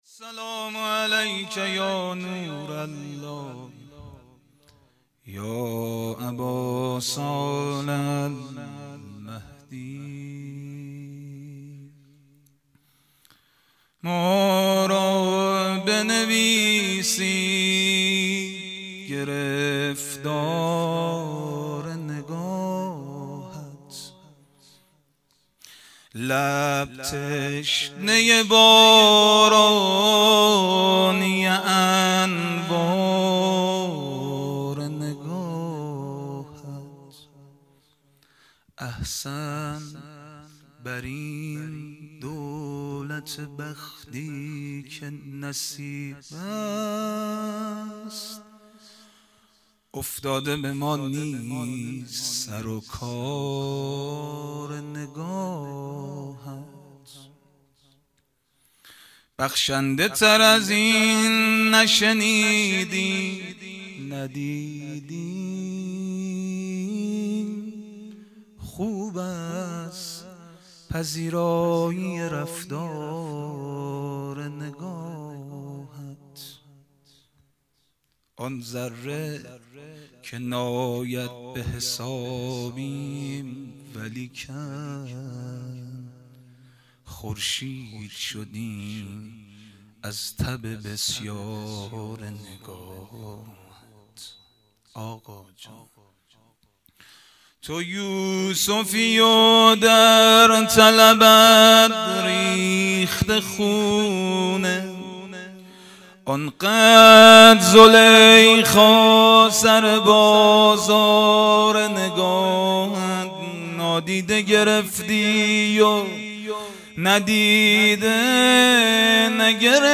مناجات با امام زمان (عج)